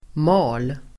Uttal: [ma:l]